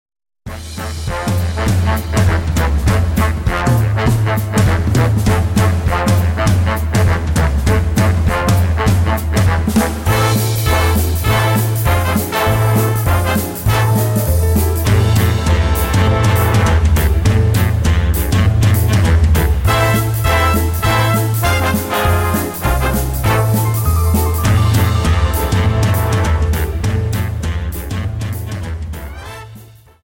Quickstep 50 Song